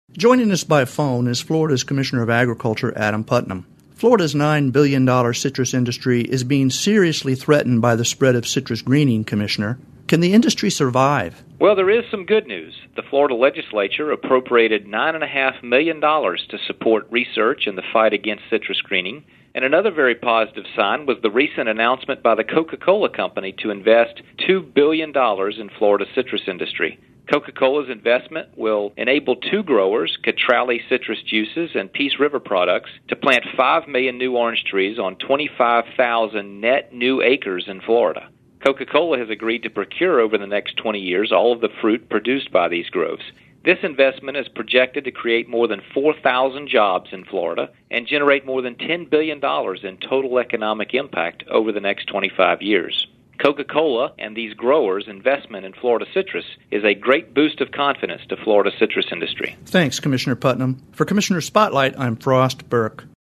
FL Ag Commissioner Adam PutnamOn this week’s Commissioner’s Spotlight, Florida’s Agriculture Commissioner Adam Putnam talks about how The Coca-Cola Company has made a long-term investment in Florida citrus by agreeing to buy all of the fruit produced from five million new orange trees planted on 25,000 net new acres by two Florida growers: Cutrale Citrus Juices and Peace River Products.